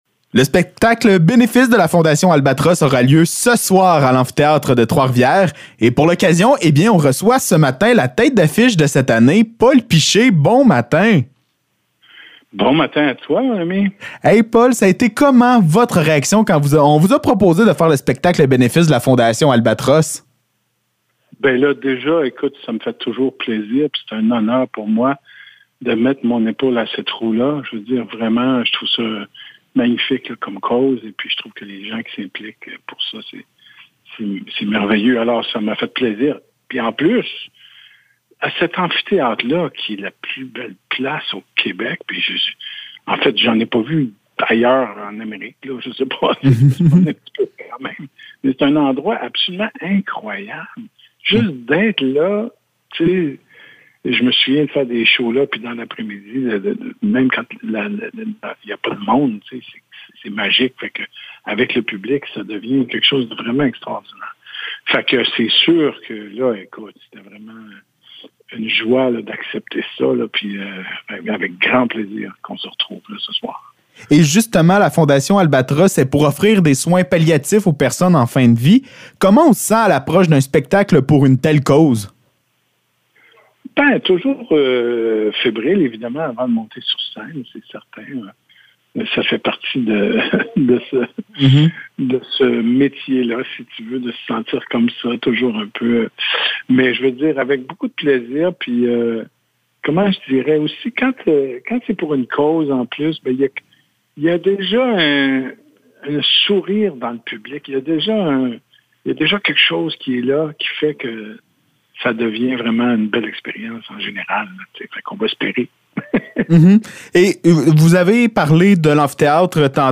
Entrevue avec Paul Piché